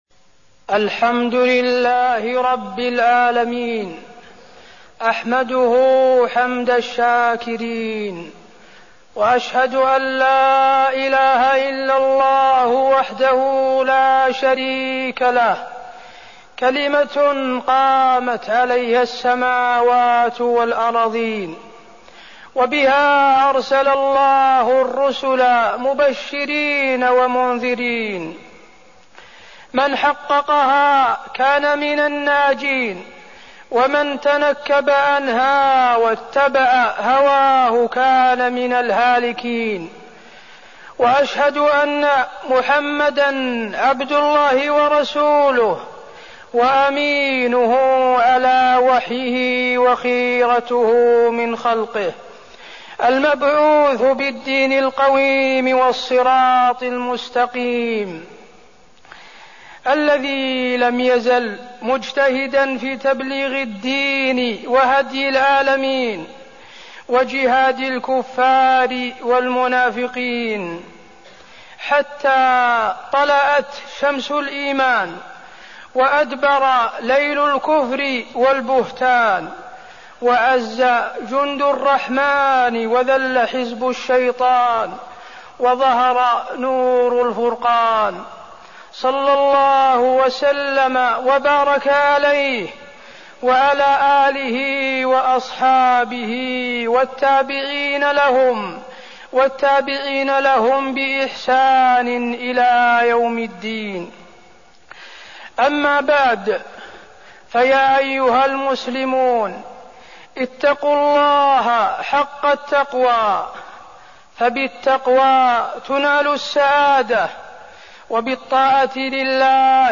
تاريخ النشر ٩ ربيع الأول ١٤١٩ هـ المكان: المسجد النبوي الشيخ: فضيلة الشيخ د. حسين بن عبدالعزيز آل الشيخ فضيلة الشيخ د. حسين بن عبدالعزيز آل الشيخ محبة الرسول صلى الله عليه وسلم